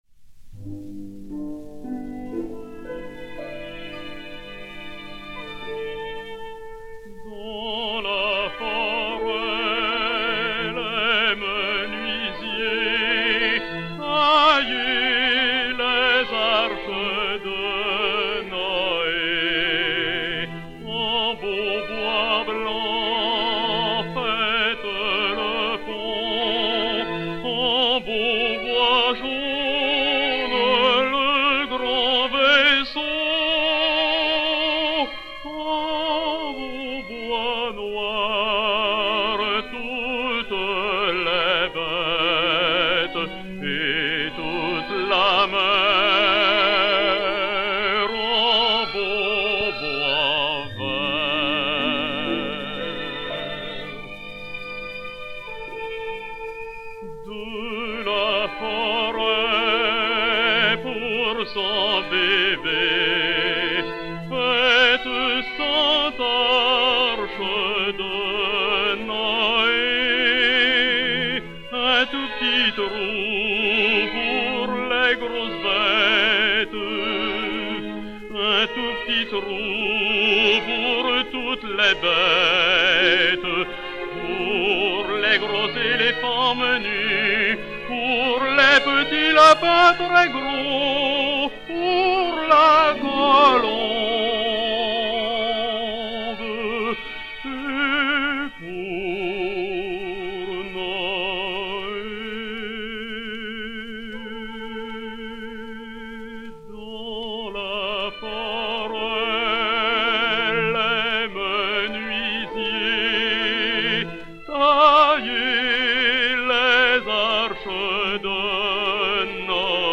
baryton français